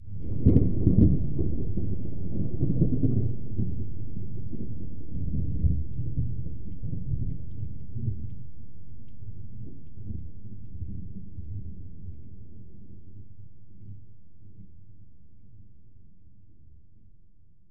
thunder1.ogg